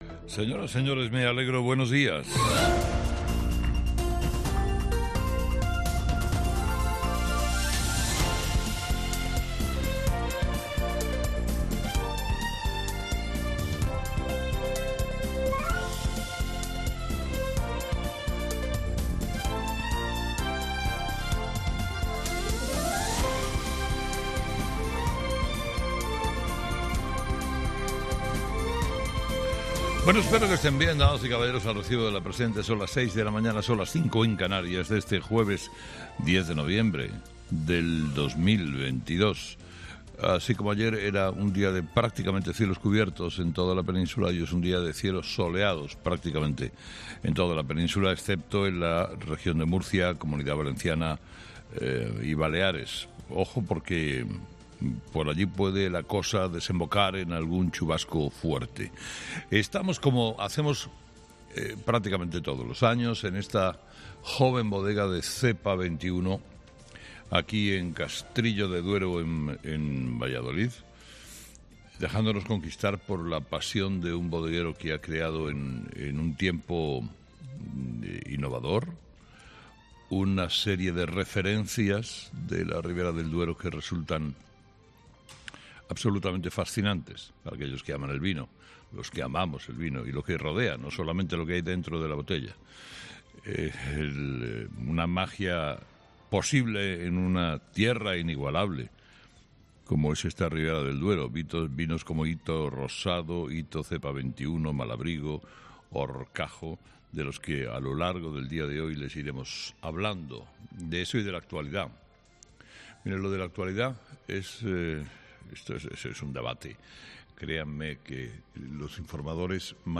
Carlos Herrera repasa los principales titulares que marcarán la actualidad de este jueves 10 de noviembre en nuestro país
Carlos Herrera, director y presentador de 'Herrera en COPE', ha comenzado el programa de este jueves analizando las principales claves de la jornada, que pasan, entre otros asuntos, por los investigadores del Instituto de Investigación Biomédica de Barcelona que han identificado las células responsables de la recaída en el cáncer de colon y de la reaparición del cáncer en otros órganos después de la extirpación del tumor primario.